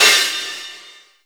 MPC4 CRASH.wav